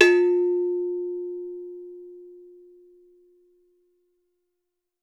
AFRCN AGOGOS